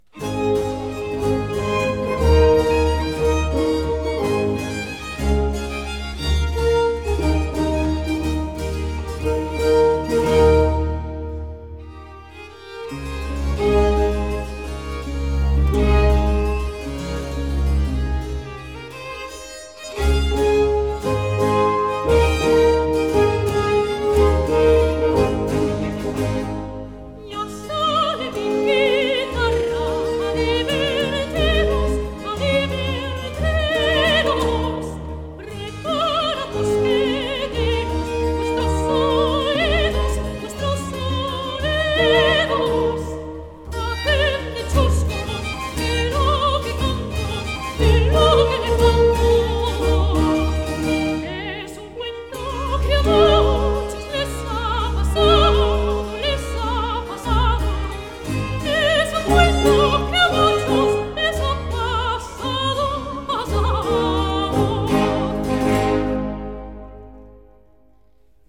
soprano
6_la_sale_mi_guitarra_no__1_andante.mp3